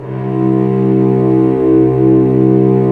Index of /90_sSampleCDs/Roland LCDP13 String Sections/STR_Vcs II/STR_Vcs6 mf Amb